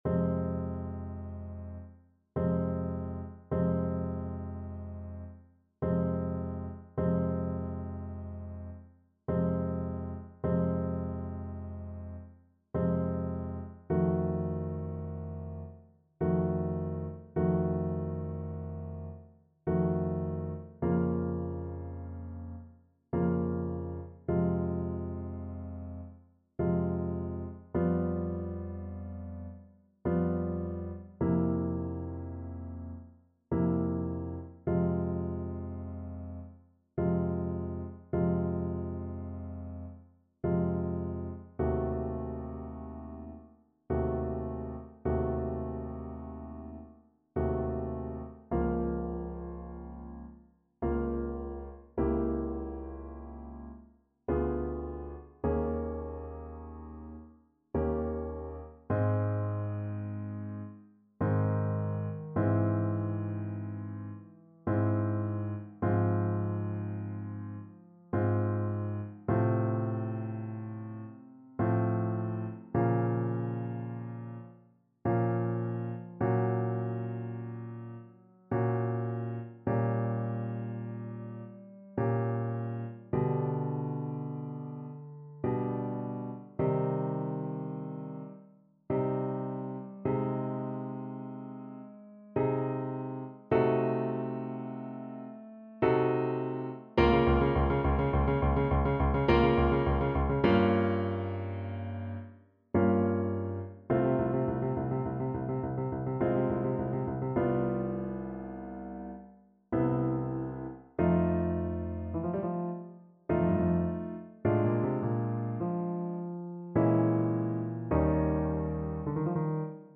3/4 (View more 3/4 Music)
Andante molto moderato (=66) ~ = 52
Classical (View more Classical Mezzo Soprano Voice Music)